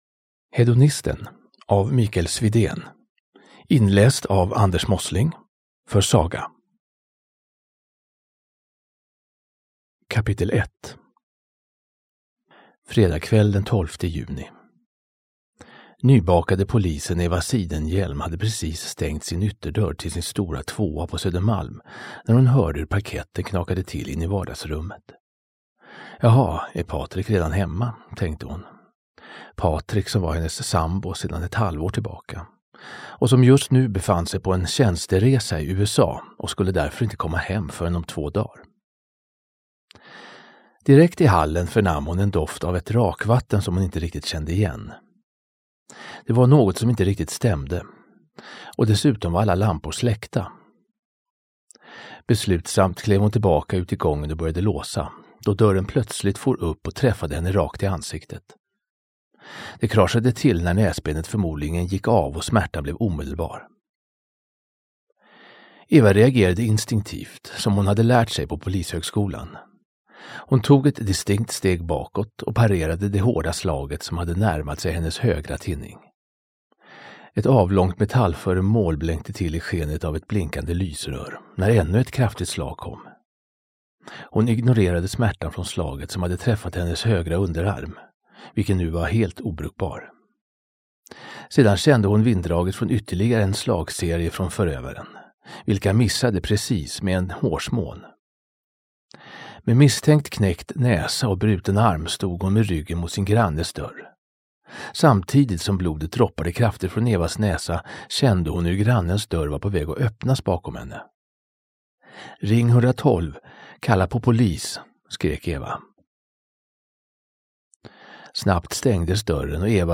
Den är inläst i olika sorters tempon, under olika skeenden i ljudboken, vilket gör att man reflekterar och tänker över vad han säger.